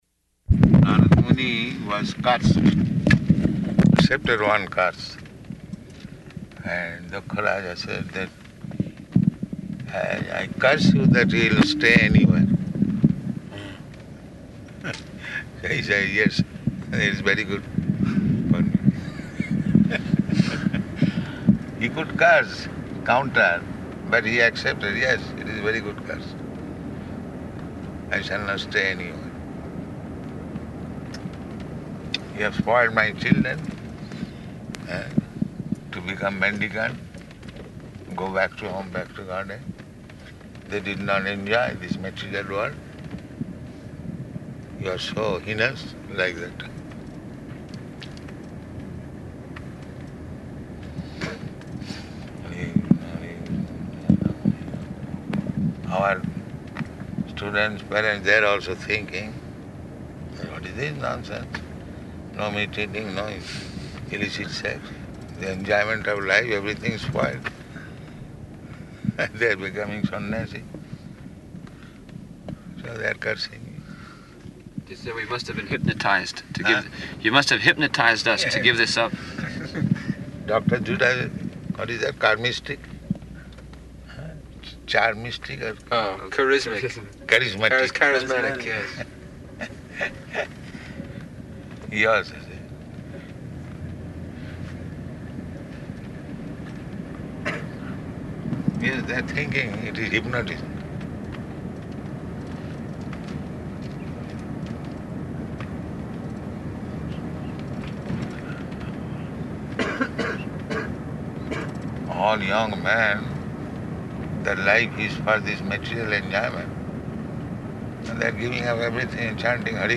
Morning Walk --:-- --:-- Type: Walk Dated: June 30th 1975 Location: Denver Audio file: 750630MW.DEN.mp3 [in car] Prabhupāda: Nārada Muni was cursed, accepted one curse.